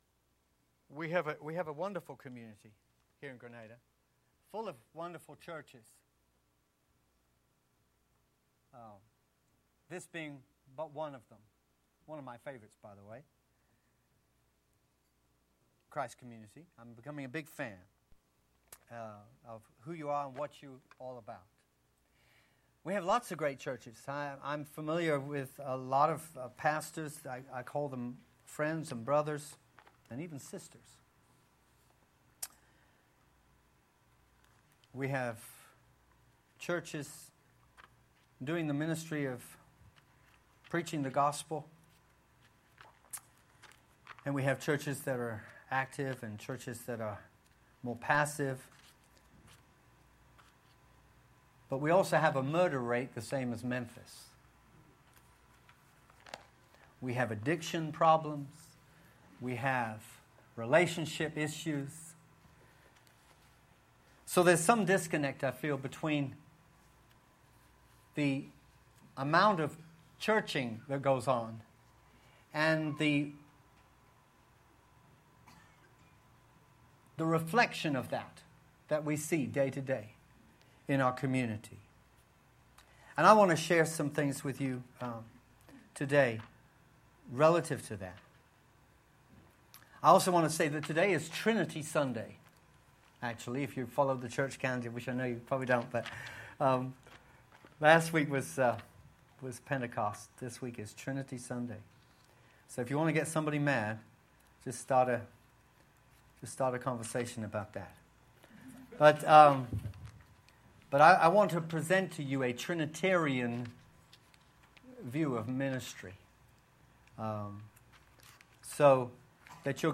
6_3_12_Sermon.mp3